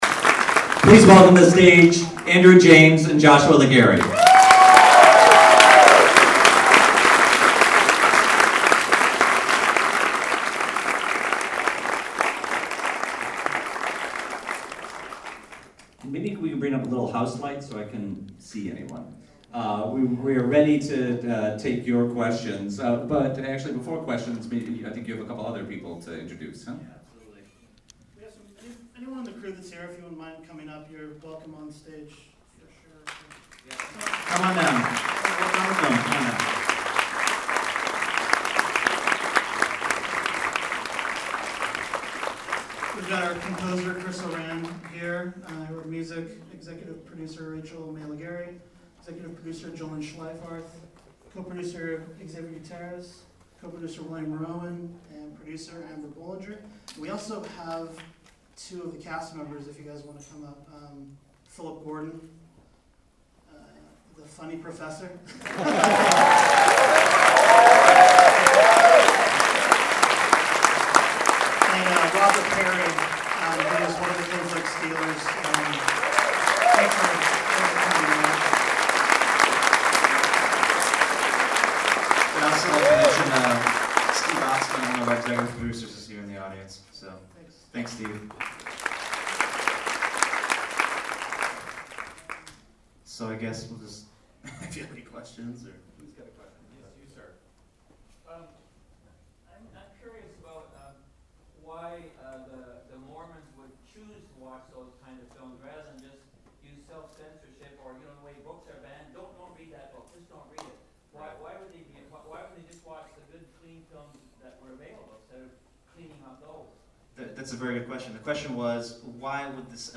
cleanflix_qa.mp3